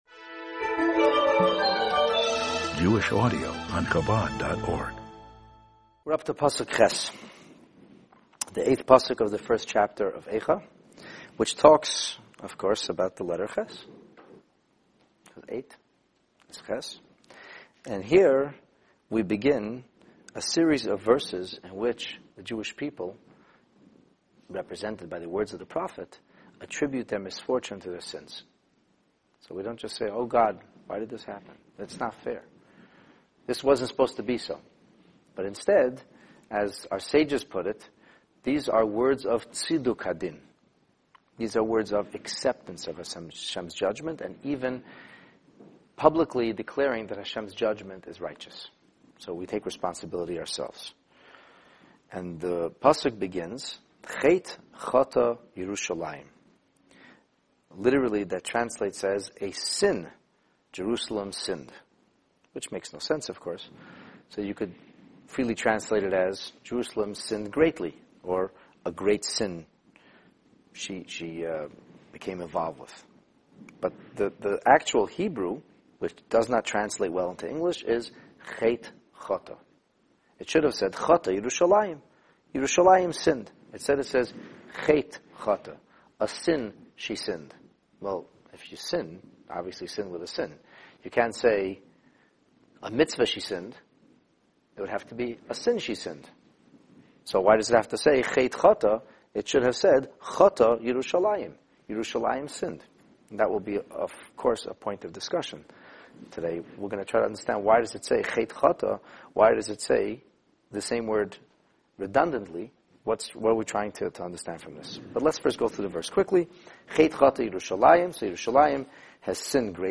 This text-based class on Megillat Eicha focuses on verse 8 of the first chapter.